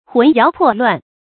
魂搖魄亂 注音： ㄏㄨㄣˊ ㄧㄠˊ ㄆㄛˋ ㄌㄨㄢˋ 讀音讀法： 意思解釋： 神魂顛倒，不能自持。